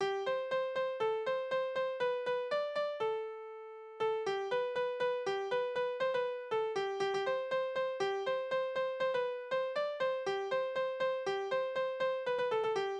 Tonart: C-Dur
Taktart: 4/8
Tonumfang: Quinte